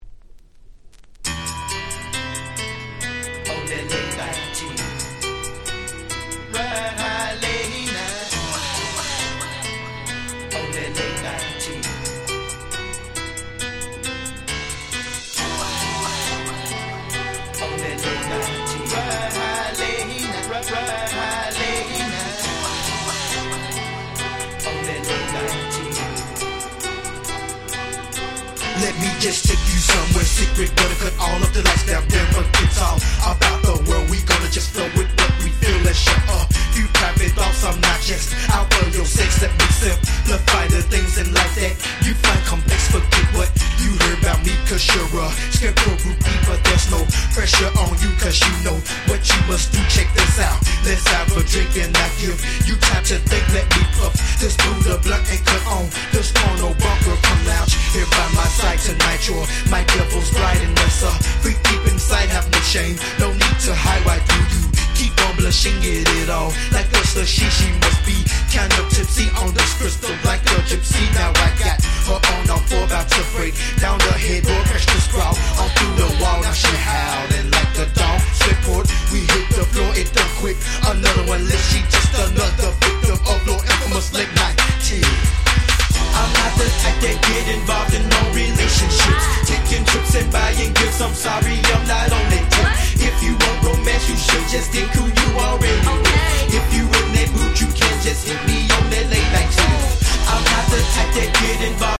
98' Smash Hit Southern Hip Hop !!